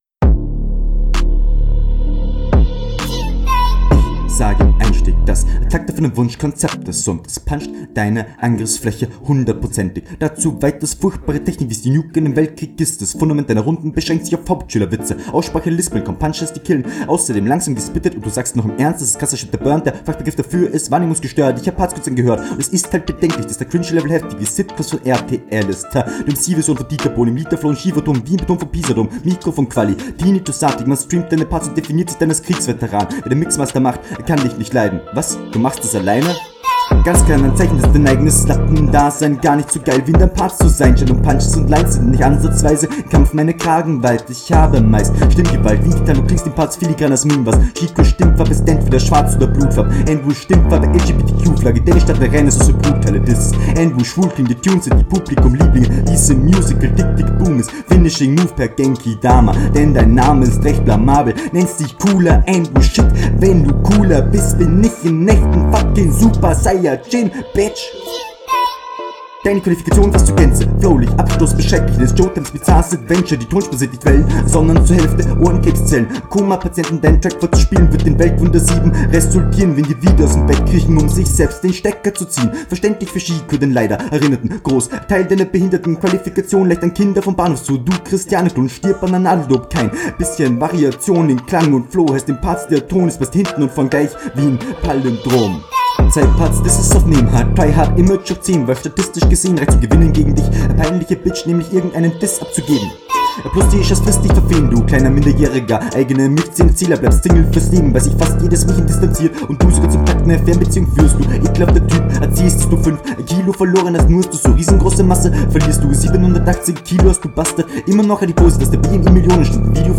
Der Versuch, schnell zu rappen, war hier eher ein Griff ins Klo - zumindest habe …